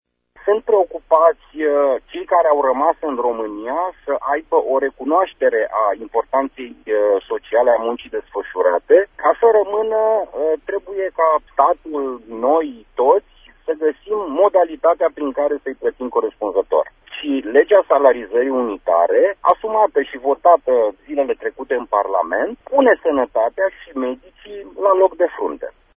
Prezent la dezbatere, președintele Comisiei de Sanatate a Camerei Deputatilor, deputatul de Mureș Florin Buicu, a declarat pentru RTM că statul trebuie să găsească soluții pentru ca medicii români să rămână în țară: